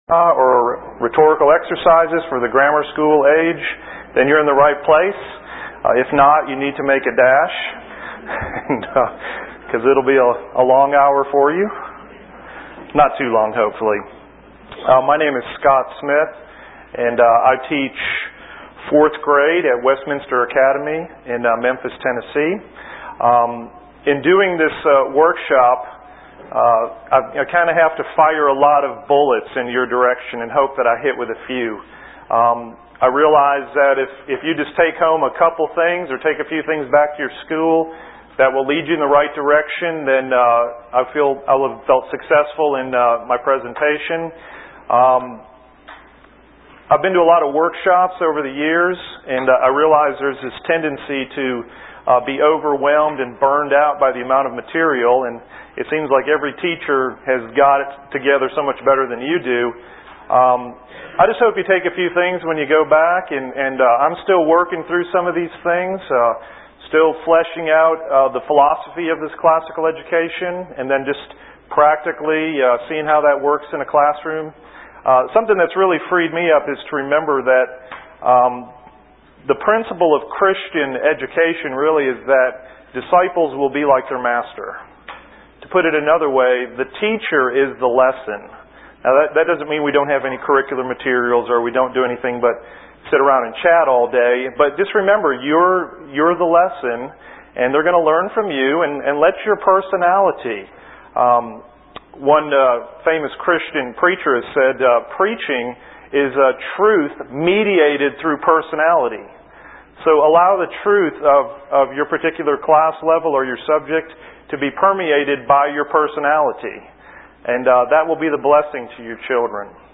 2003 Workshop Talk | 1:07:07 | K-6, Rhetoric & Composition
The Association of Classical & Christian Schools presents Repairing the Ruins, the ACCS annual conference, copyright ACCS.